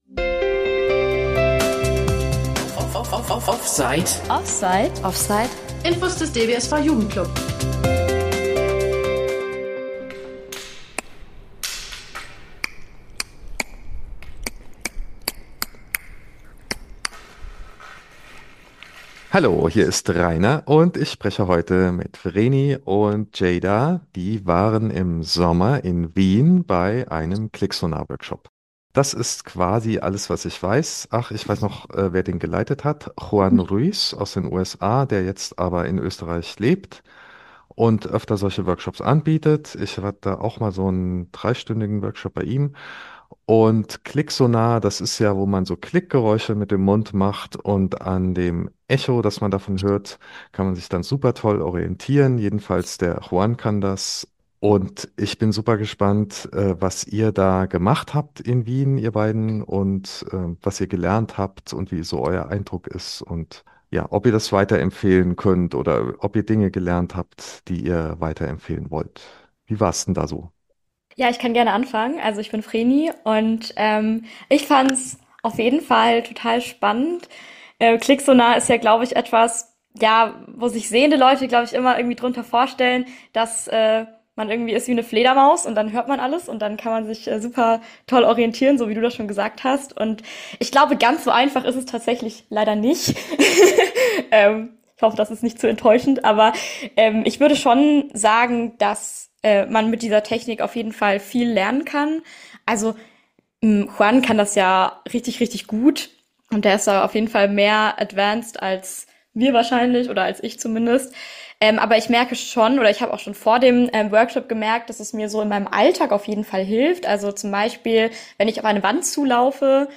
Interview mit zwei Teilnehmenden an einem Klicksonar-Seminar